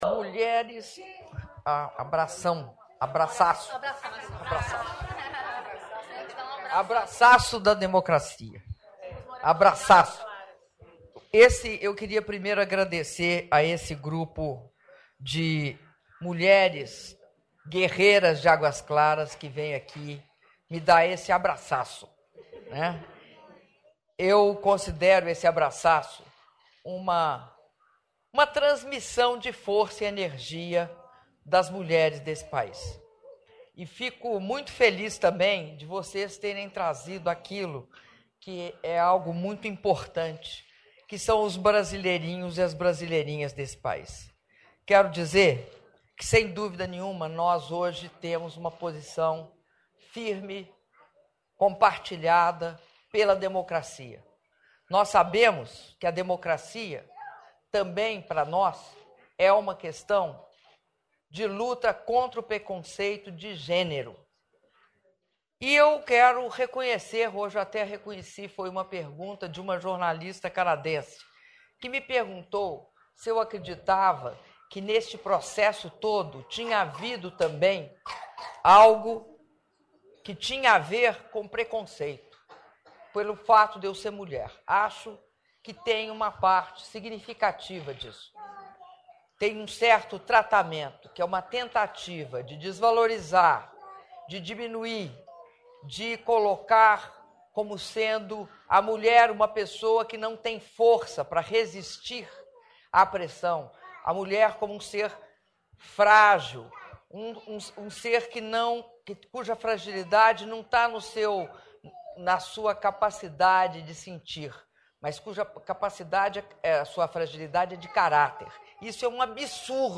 Áudio da fala da presidenta da República, Dilma Rousseff, durante encontro com organizadoras do evento Abraçaço da Democracia - Mulheres com Dilma - Palácio do Planalto (03min34s)